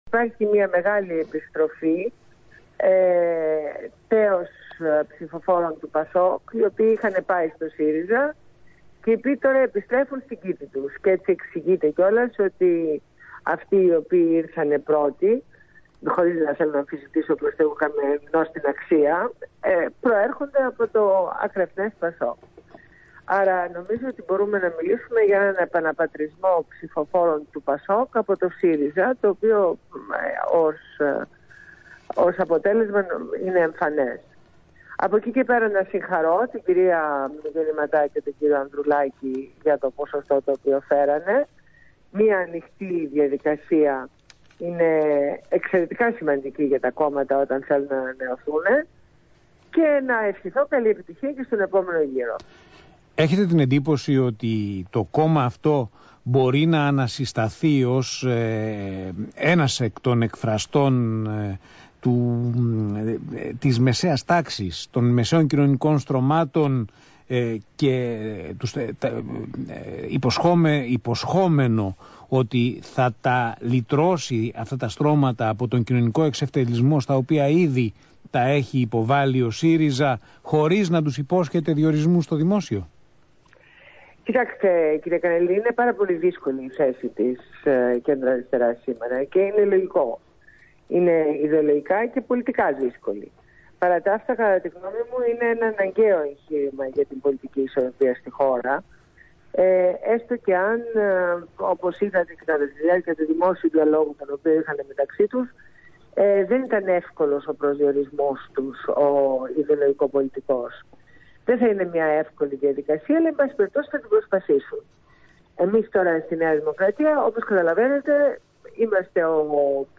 Συνέντευξη στο ραδιόφωνο Αθήνα 9,84